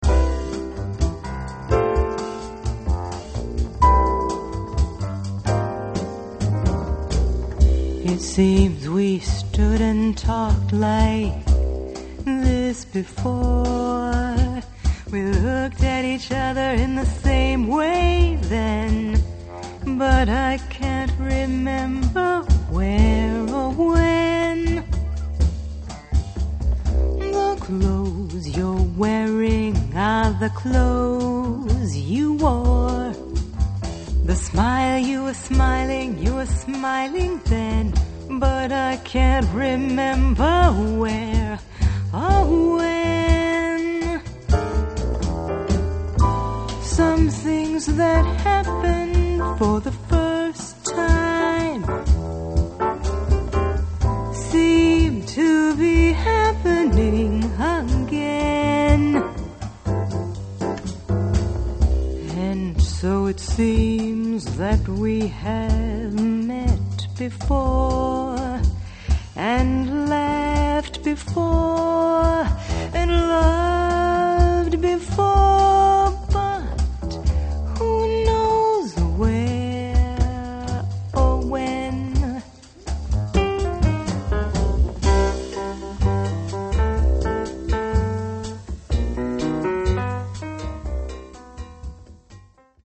Jazz Vocalist
vocals
piano (3, 6, 7, 10, 12, 14, 15, 17)
bass (3, 4, 6, 7, 10, 12, 15, 17)
drums (1, 2, 4, 6, 7, 8, 9, 10, 11, 12. 15, 17)